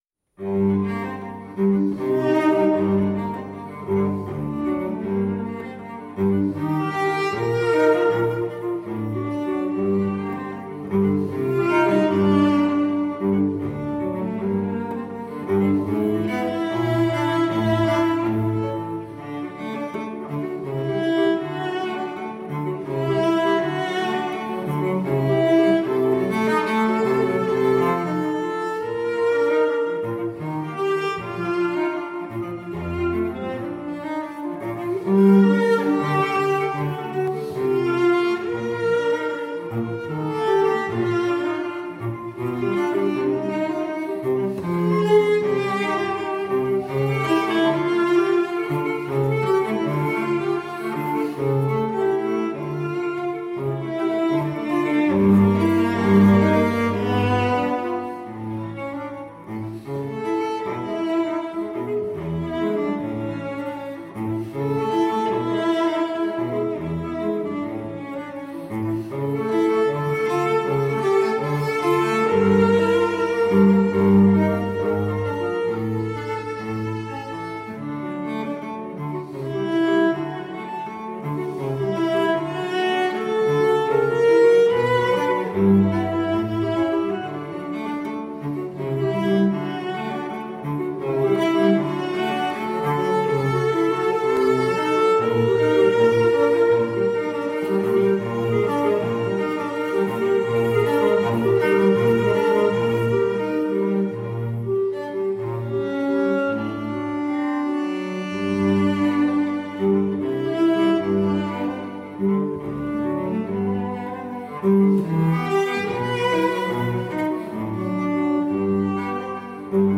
Classical, Romantic Era, Instrumental Classical, Cello